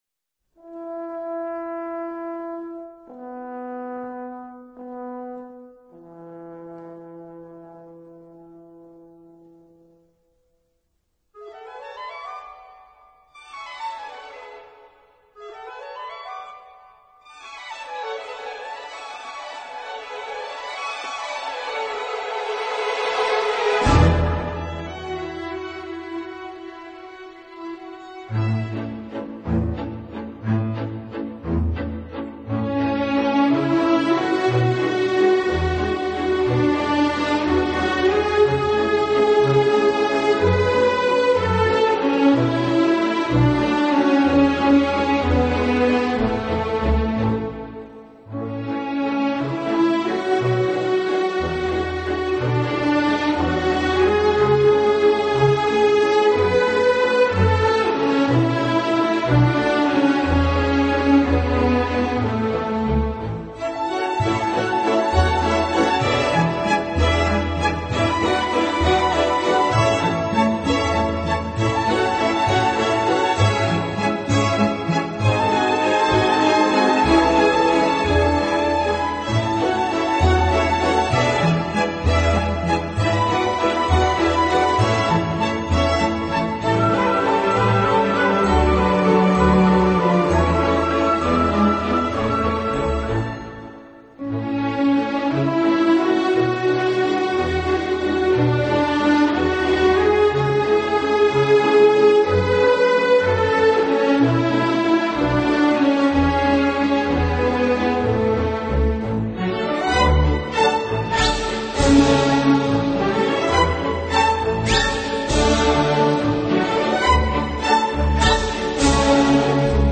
小提琴专辑
音乐类型：Classic 古典
音乐风格：Classical,Waltz